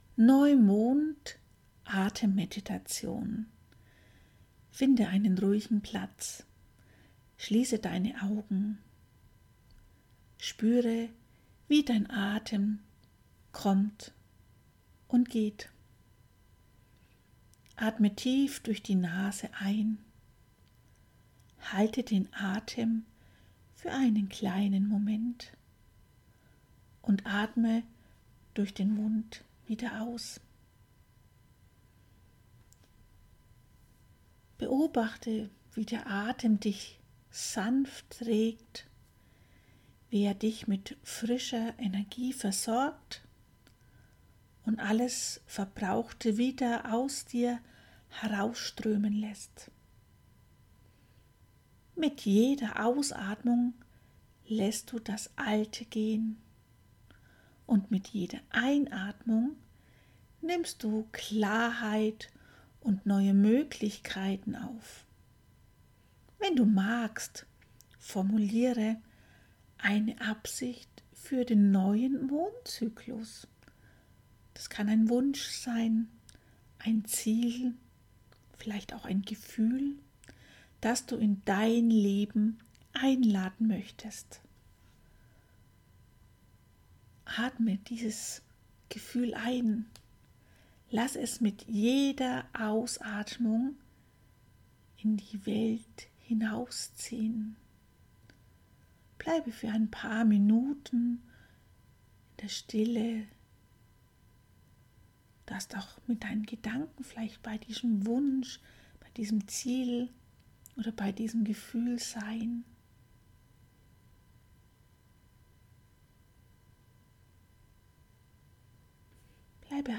Atemmeditation zum Neumond
Hier findest du die Audioaufnahme zum Mitmachen und Loslassen:
Atemmeditation-zum-Neumond.mp3